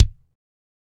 Index of /90_sSampleCDs/Northstar - Drumscapes Roland/KIK_Kicks/KIK_A_C Kicks x
KIK A C K09R.wav